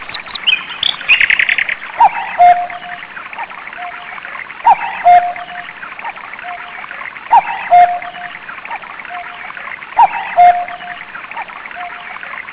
From barking dogs to meowing cats and chirping birds, each clock has its own unique voice.